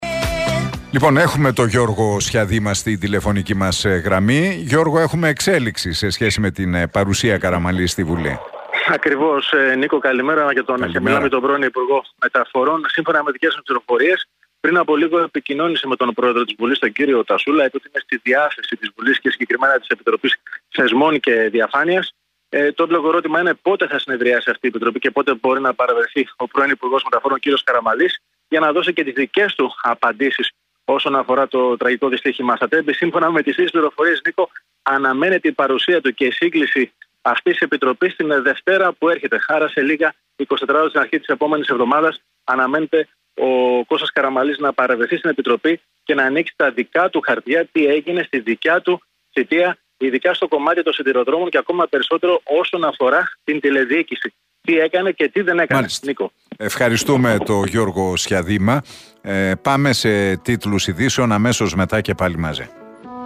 στην εκπομπή του Νίκου Χατζηνικολάου